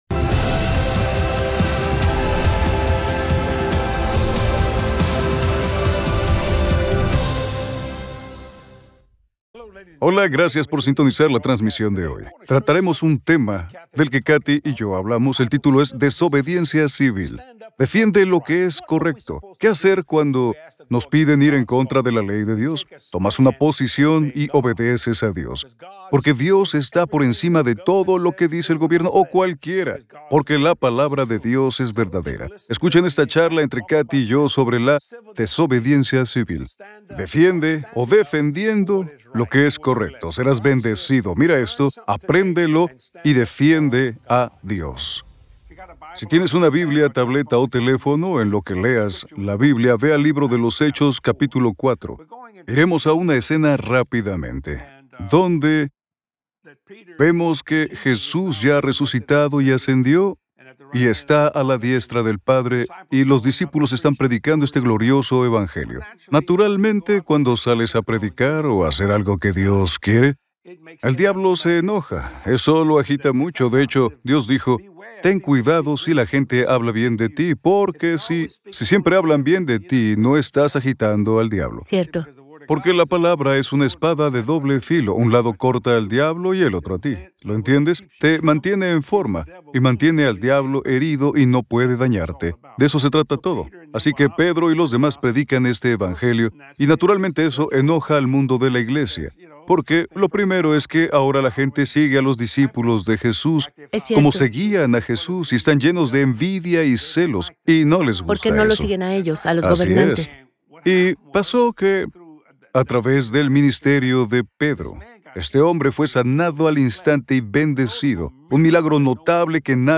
En esta poderosa charla en la SALA DE JUNTAS